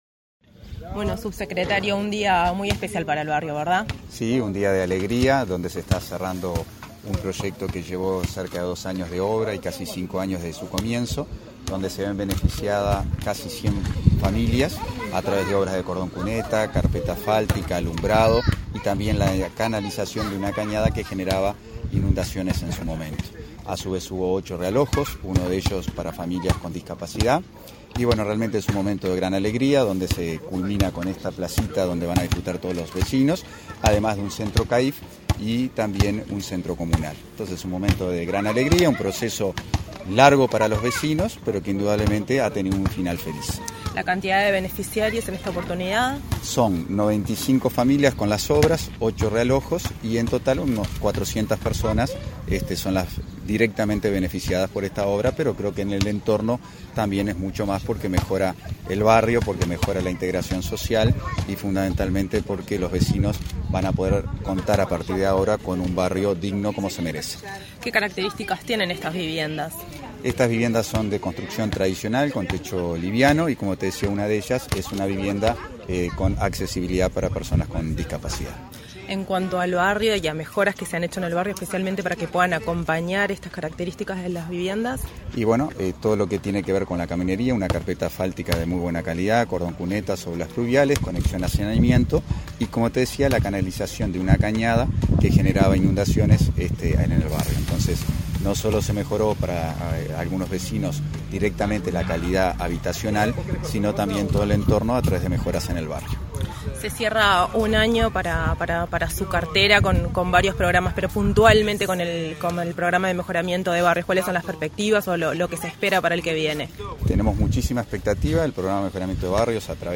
Declaraciones del subsecretario de Vivienda y Ordenamiento Territorial, Tabaré Hackenbruch